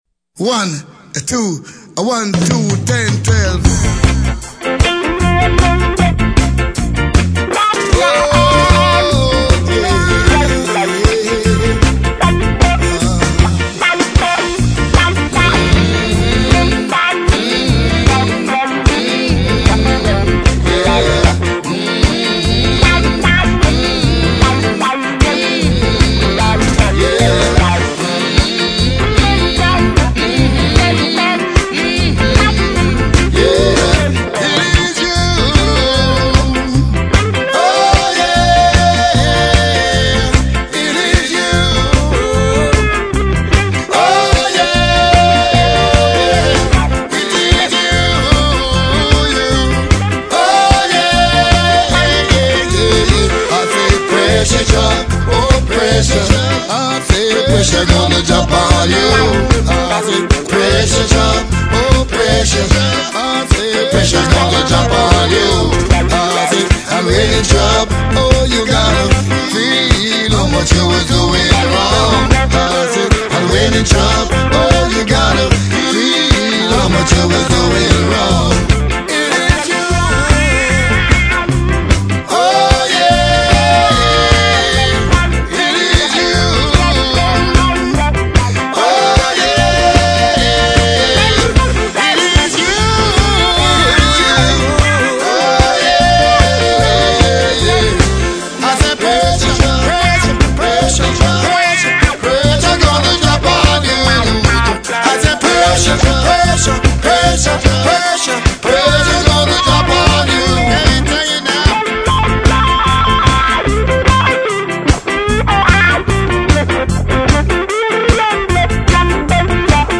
It's 15 tracks of non-stop Rockin' Reggae!